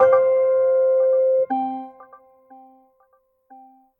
Cisco Webex Ringback
ringback.mp3